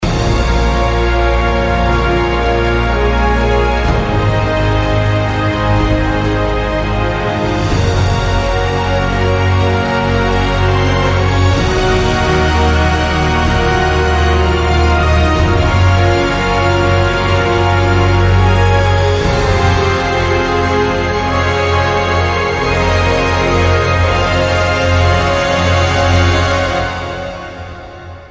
Los mas victoriosos, emotivos o solemnes